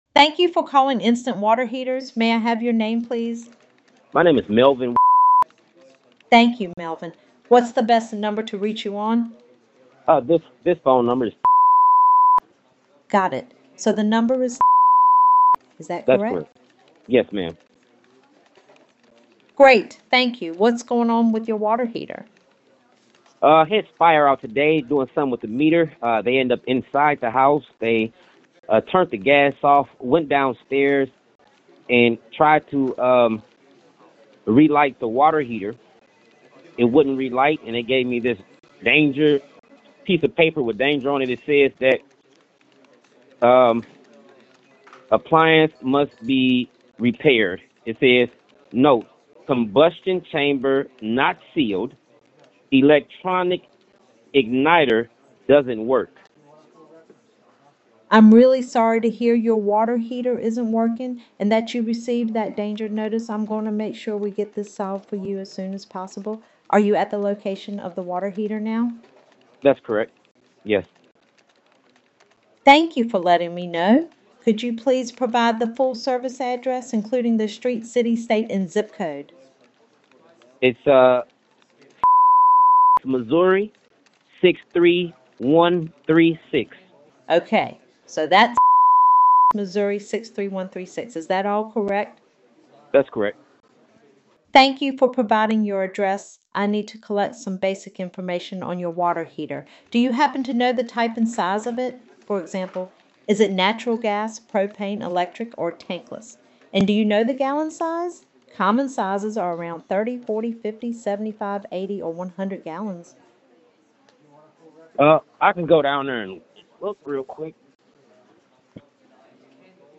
Plumbing Voice Agent
01-call-instant-water-heaters.mp3